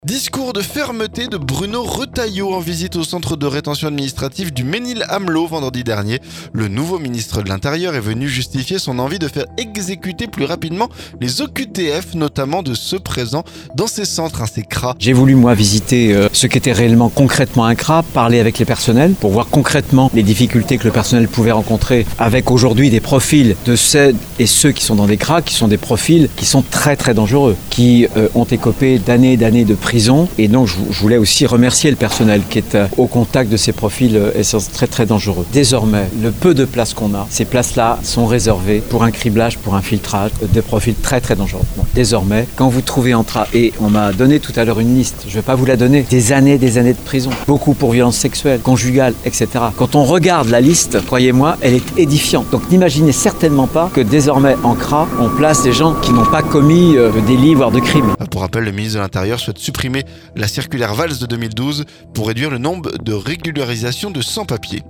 Discours de fermeté de Bruno Retailleau en visite au Centre de Rétention Administrative du Mesnil-Amelot vendredi dernier. Le nouveau ministre de l’Intérieur est venu justifier son envie de faire exécuter plus rapidement les OQTF, notamment de ceux présents dans ces centres, ces CRA.